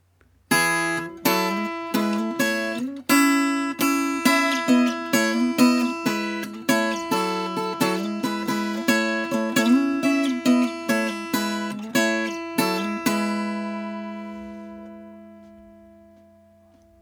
Nashville-strung guitar imitating Mountain Dulcimer (1)
16-nashville-dulcimer-1.mp3